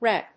/rép(米国英語), rep(英国英語)/